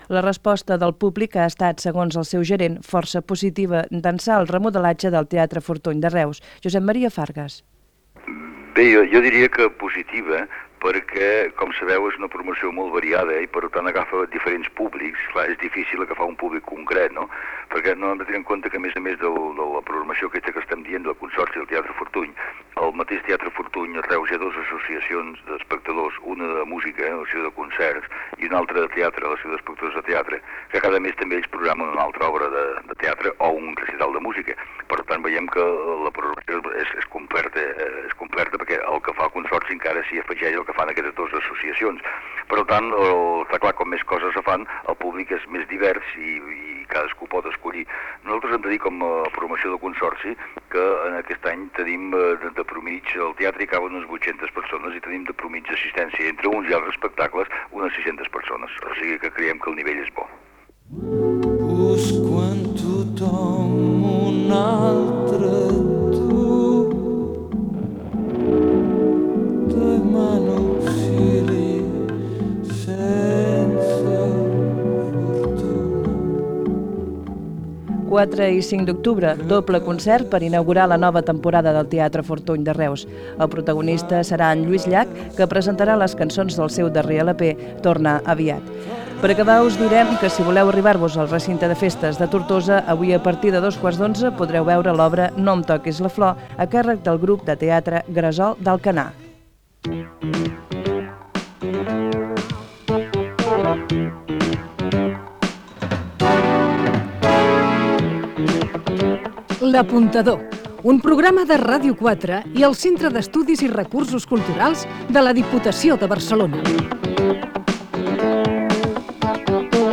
9fe8d8dae1523ea4f30345f4ba6bd4905e109545.mp3 Títol Ràdio 4 Emissora Ràdio 4 Cadena RNE Titularitat Pública estatal Nom programa L'apuntador Descripció Remodelació del Teatre Fortuny de Reus i propers espectacles. Careta de sortida del programa